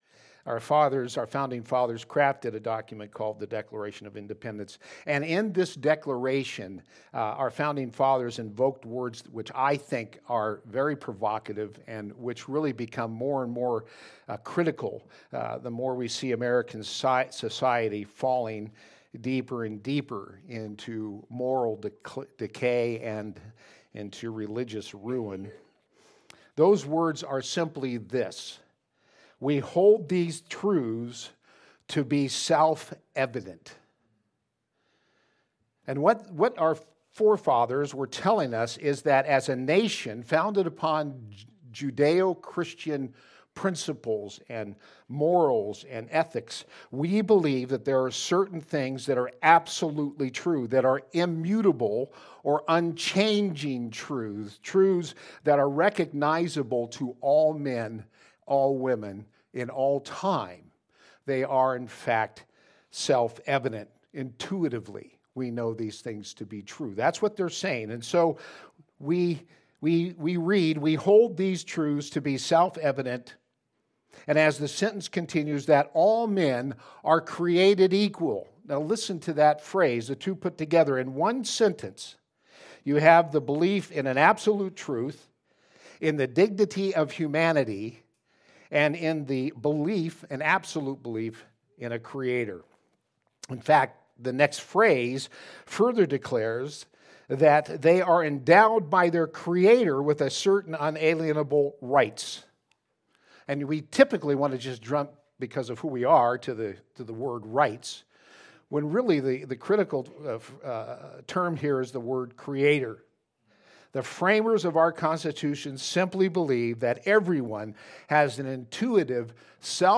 Passage: Romans 1:18-20 Service Type: Sunday Service